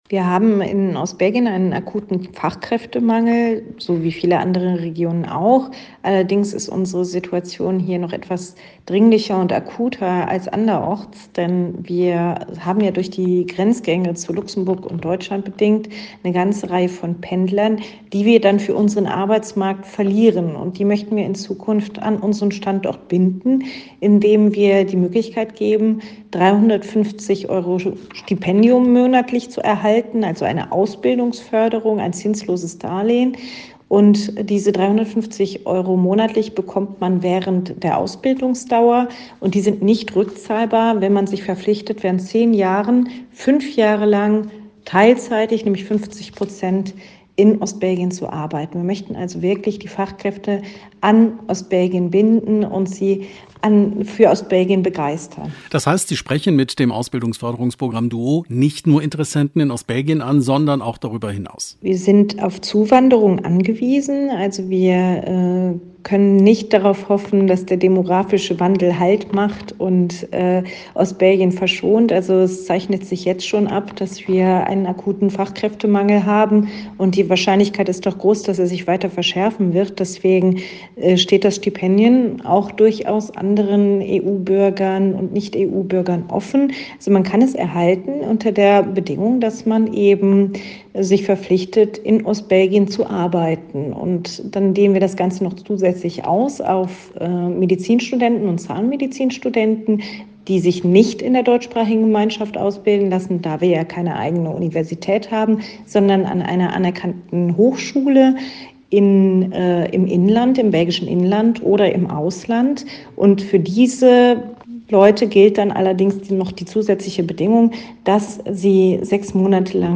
sprach mit Bildungsministerin Lydia Klinkenberg und wollte zunächst wissen, was man damit erreichen will.